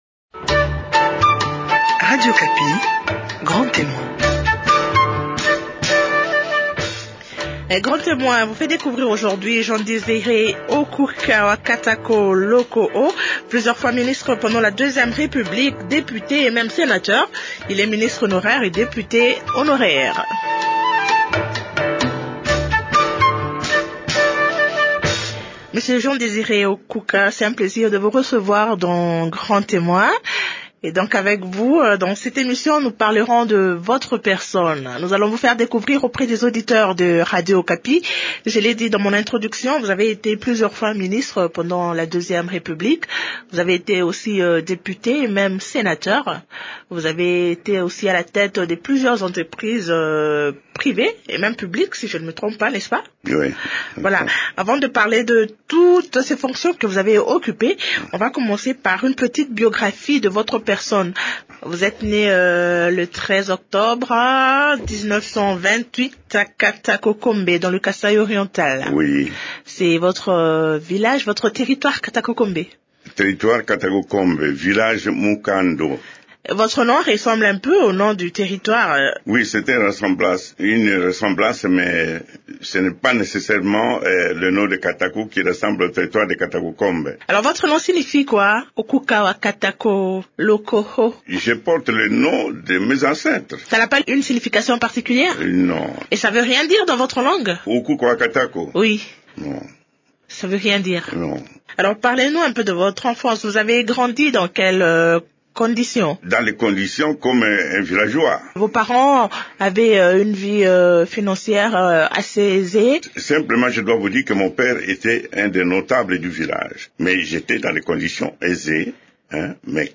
L’invité de grand témoin cette semaine est Jean Desiré Okuka wa Katako Lokoho. Il a été ministre dans plusieurs gouvernements sous le régime du Marechal Mobutu.